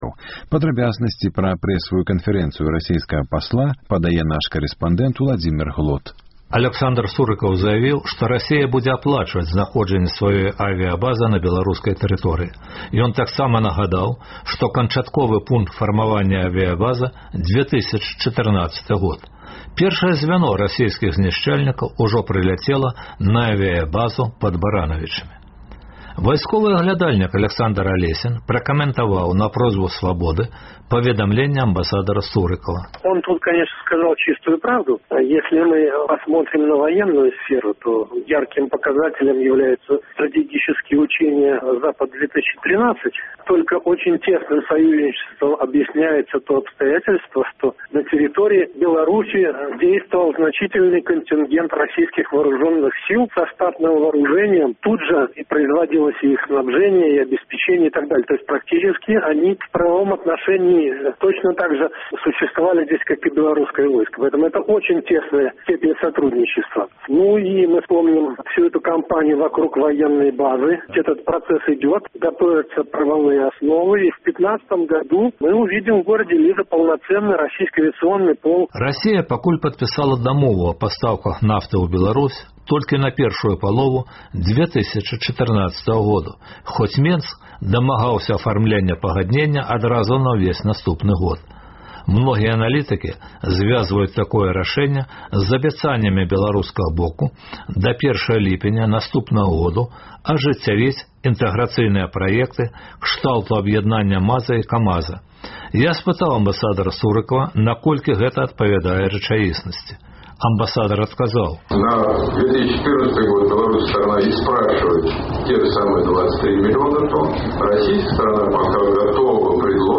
Сярод тэмаў: Рэпартаж нашага карэспандэнта зь менскіх вуліц. Сёньня ў сталіцы і іншых буйных гарадах Беларусі заплянаваная акцыя «Стоп-падатак-2».